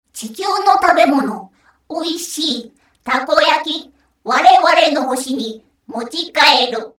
ボイス
キュート女性